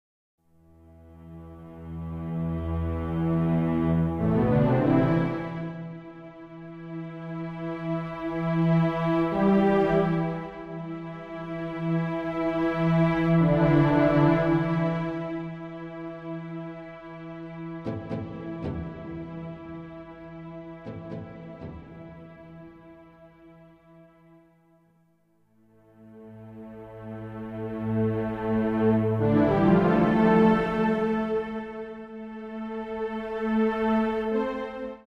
Sound Track
SYNTHESIZER